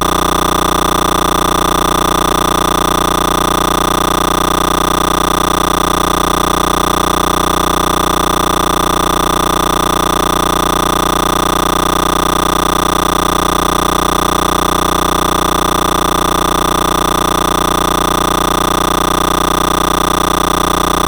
Coming out of TX: